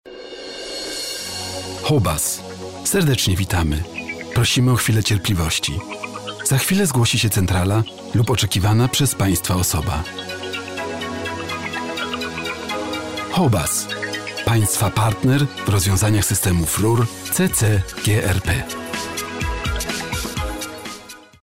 Sprecher polnisch, Übersetzer und Autor von Theaterstücken, Kurzerzählungen und Novellen.
Sprechprobe: Werbung (Muttersprache):